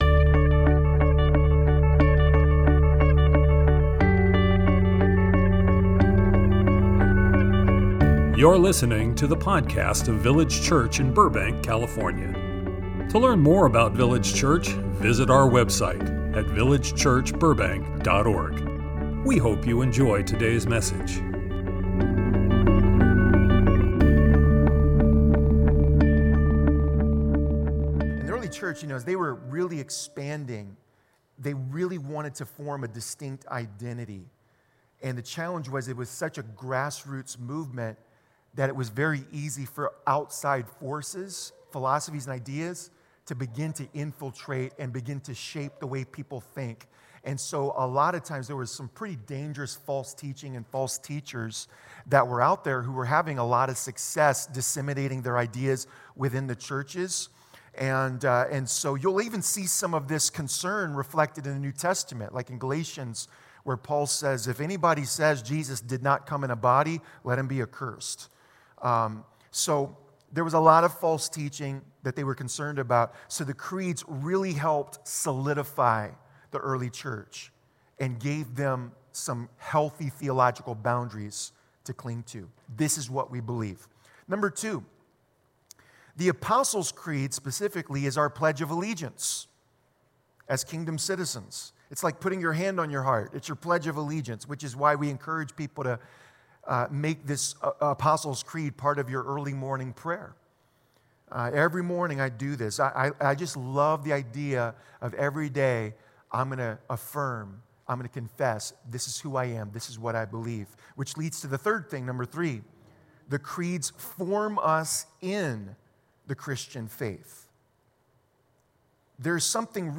Church History Seminar - The Creeds